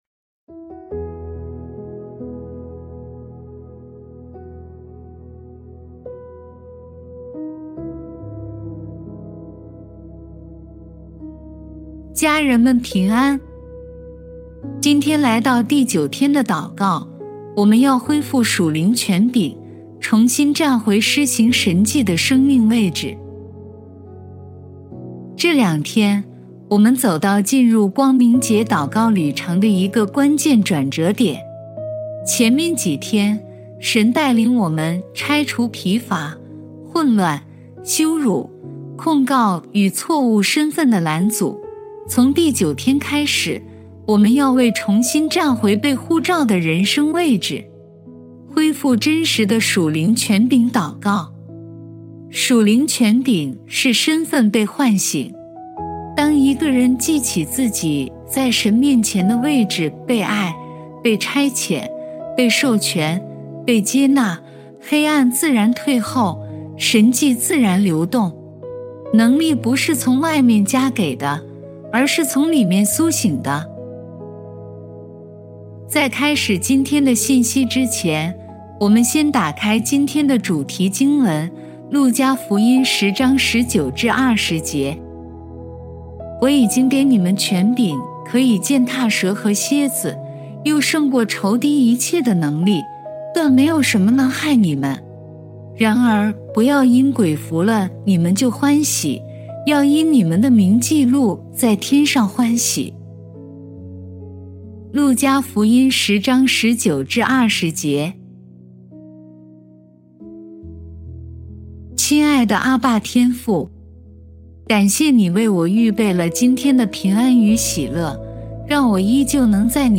本篇由微牧之歌撰文祷告及语音